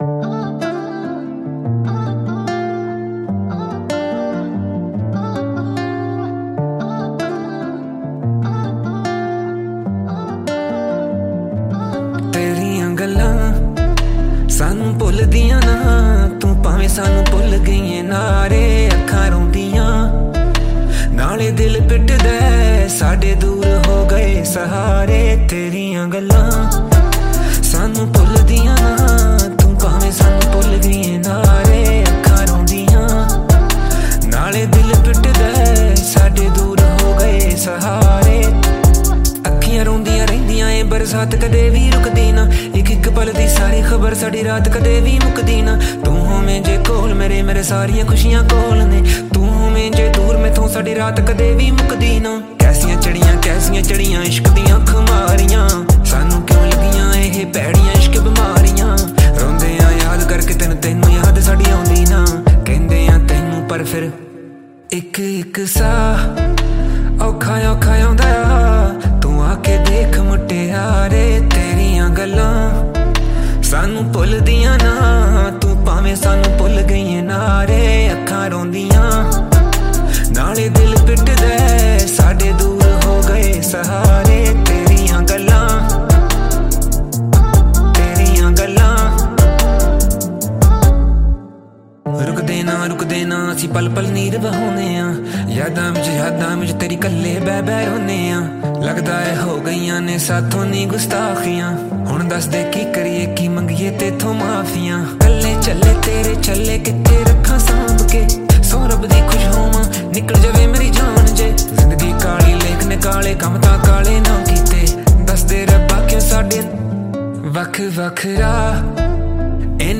New Punjabi Song